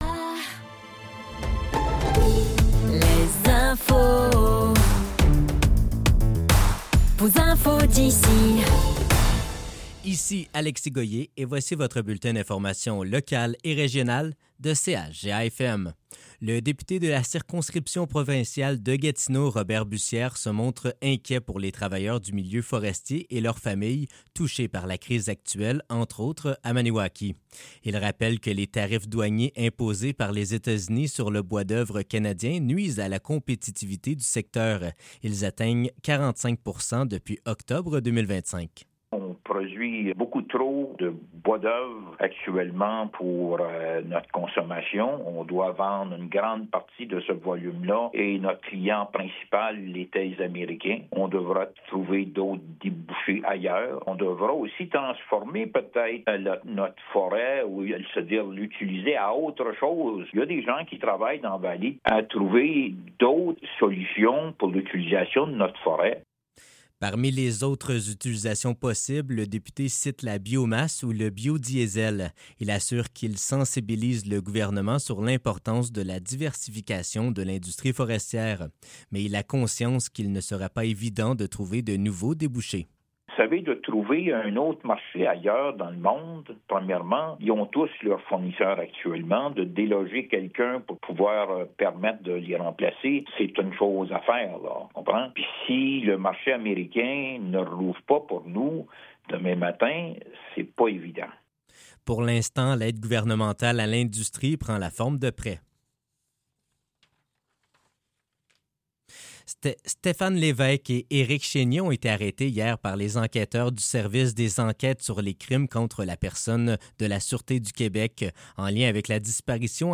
Nouvelles locales - 15 janvier 2026 - 15 h